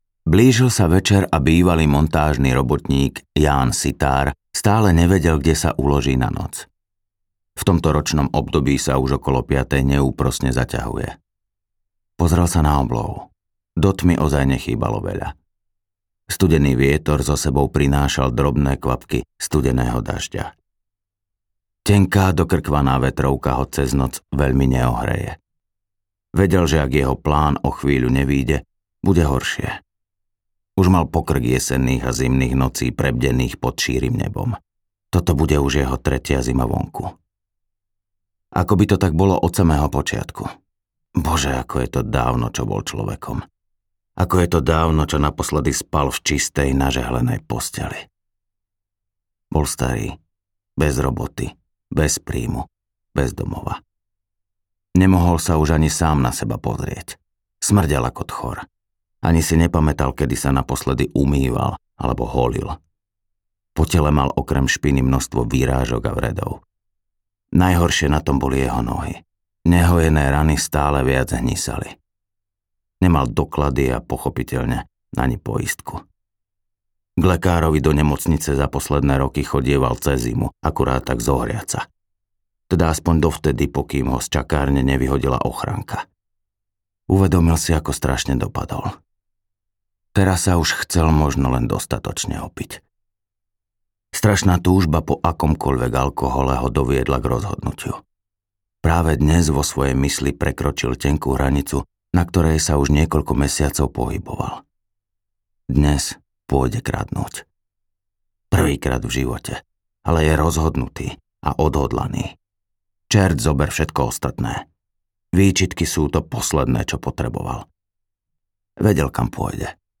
Krkavčí súd audiokniha
Ukázka z knihy
krkavci-sud-audiokniha